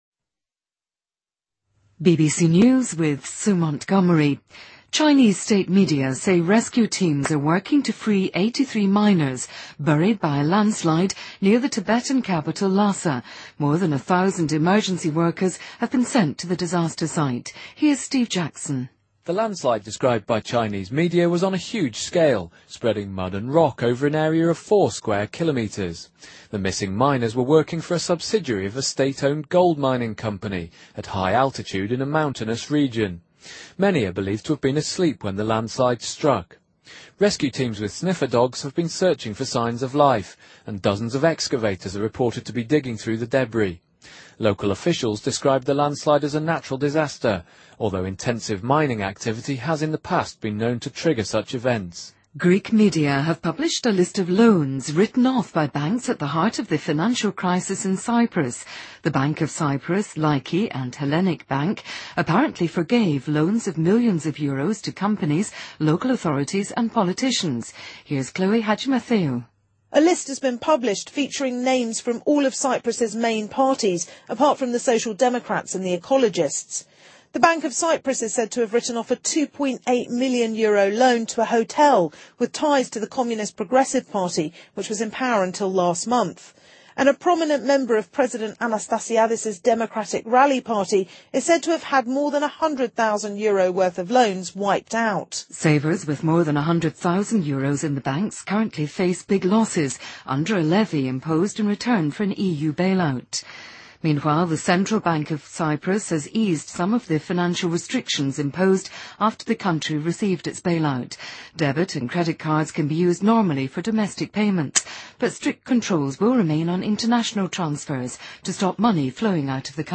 BBC news,2013-03-30